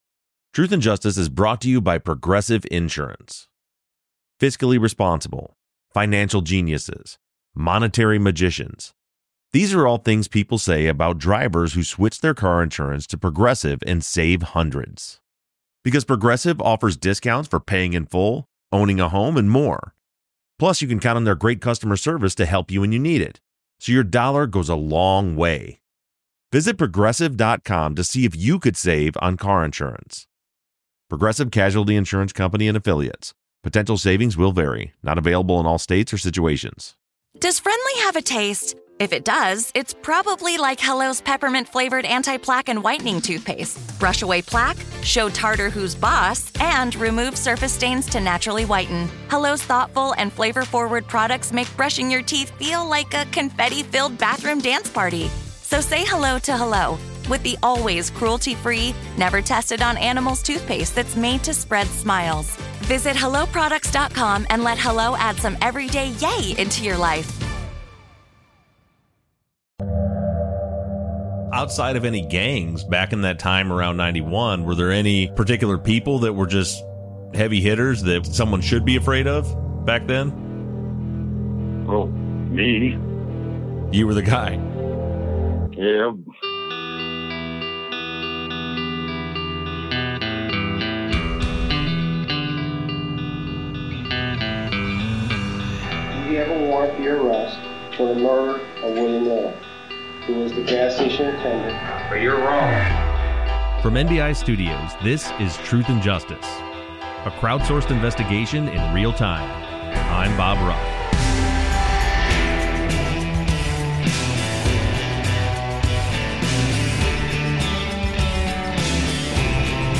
interviews a man who adds some insight into the 90's prison gang "The Northsiders"